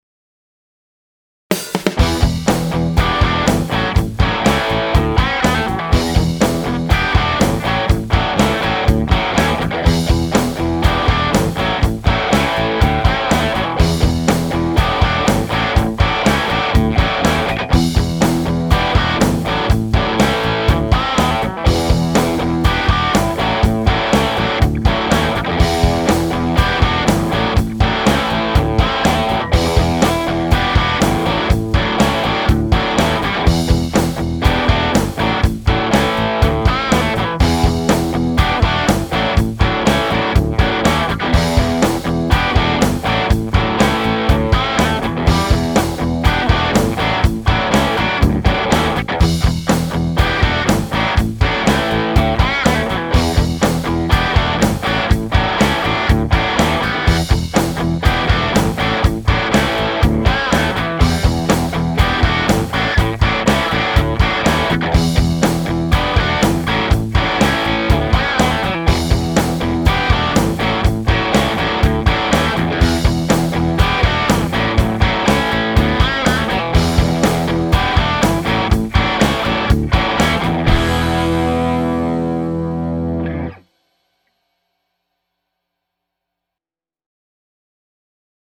Tässä sama audiona: Tallahumbuckerit **** I recorded all humbucker-equipped guitars I could muster back to back. Here’s the audio: Bridge Humbuckers **** Järjestys – Running order Tokai LC-85 Epiphone G-400 Kasuga ES-335-copy Epiphone Les Paul Standard with EMG HZs Hamer USA Studio Custom ****
humbucker-shoot-out.mp3